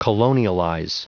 Prononciation du mot : colonialize
colonialize.wav